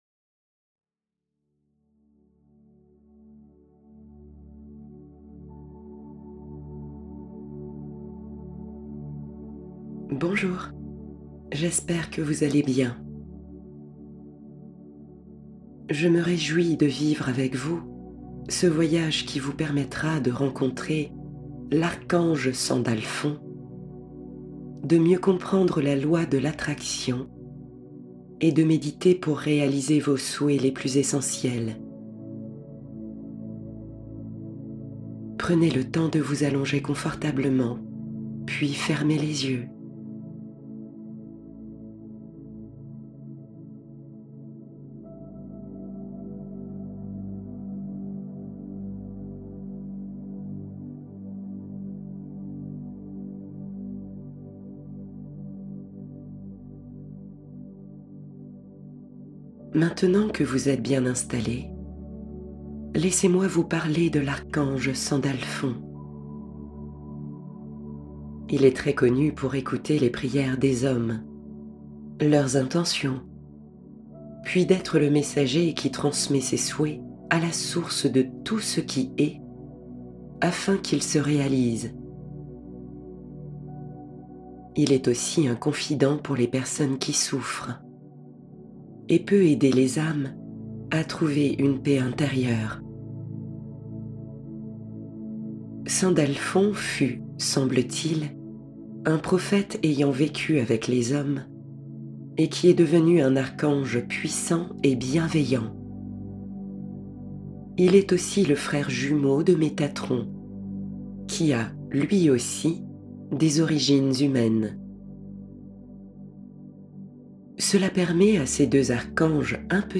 Méditation guidée archange Sandalphon
• Coffret voix seule immersion totale offert – 13 versions sans musique pour une pratique différente